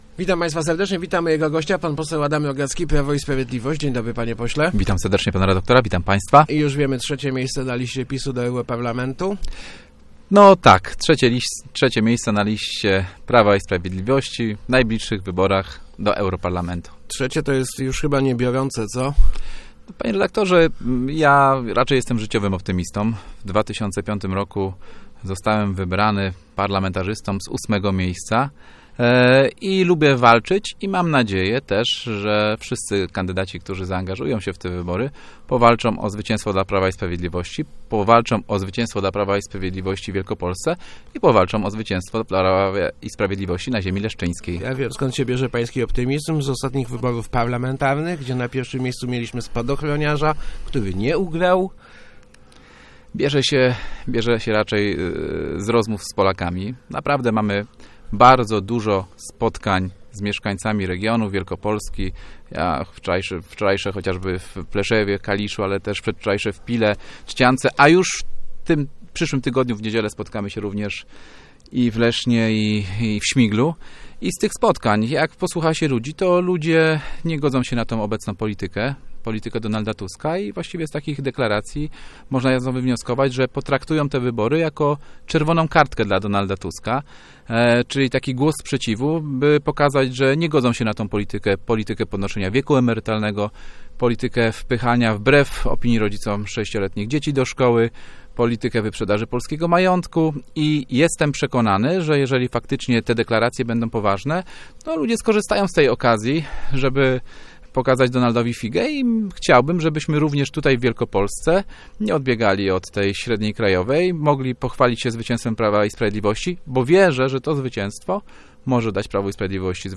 Myślę, że obywatele w wyborach do Europarlamentu pokażą czerwoną kartkę Donaldowi Tuskowi - mówił w Rozmowach Elki poseł PiS Adam Rogacki. PiS w Wielkopolsce liczy na dwa mandaty.